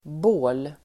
Uttal: [bå:l]